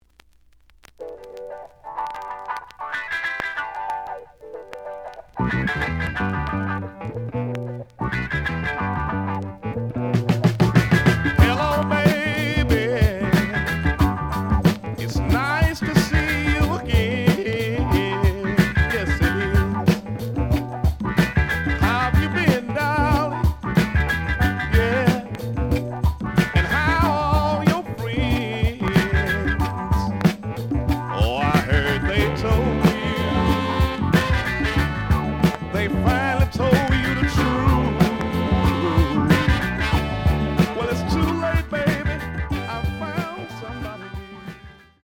The audio sample is recorded from the actual item.
●Genre: Funk, 70's Funk
Some click noise on B side due to scratches.)